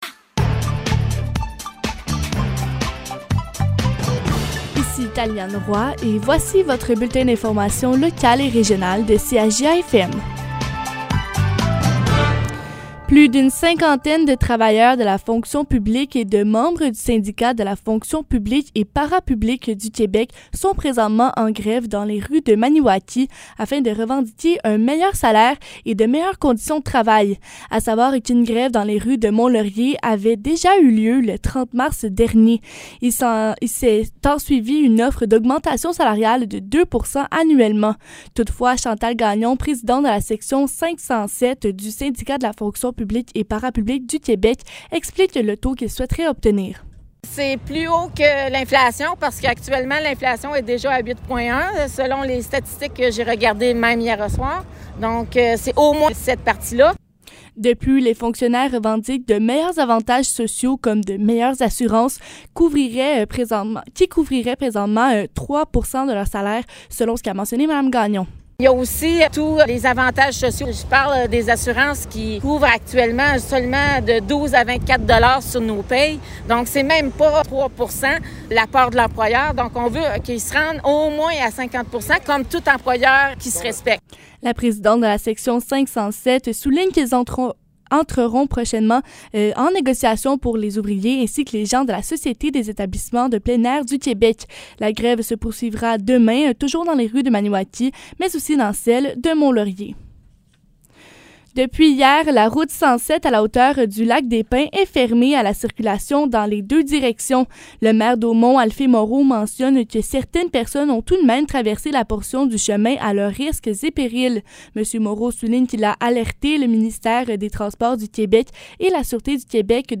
Nouvelles locales - 26 avril 2022 - 15 h